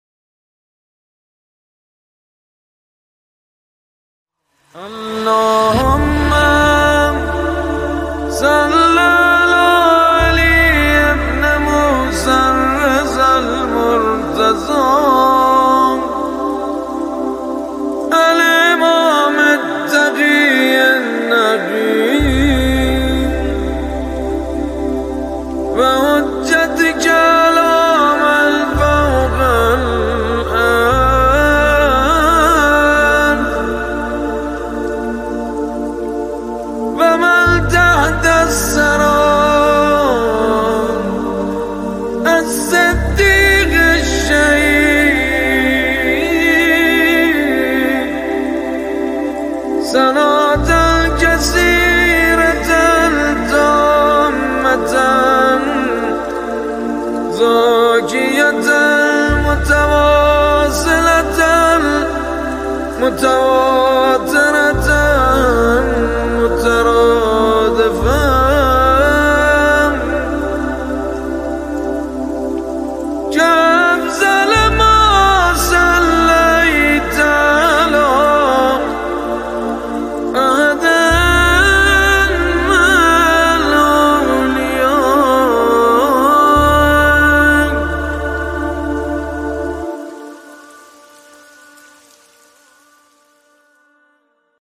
قرائت صلوات خاصه توسط مادحین برتر کشور :(برای شنیدن روی اسامی مادحین کلیک کنید)